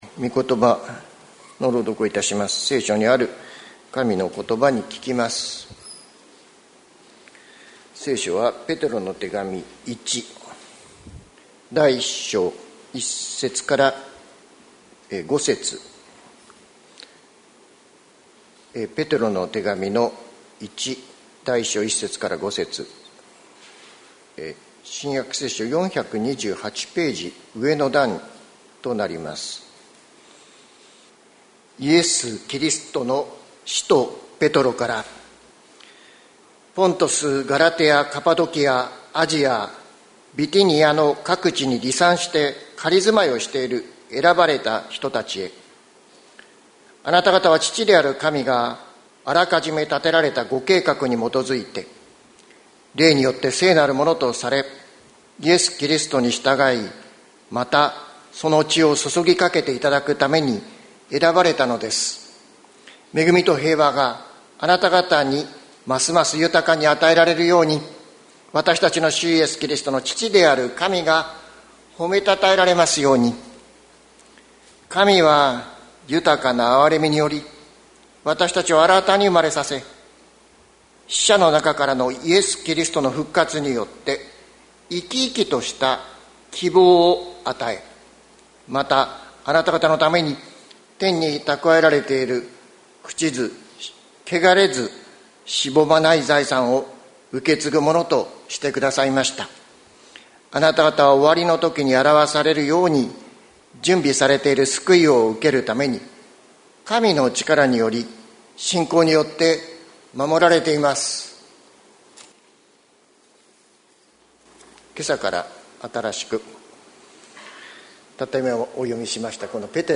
2024年08月04日朝の礼拝「生き生きとした希望」関キリスト教会
説教アーカイブ。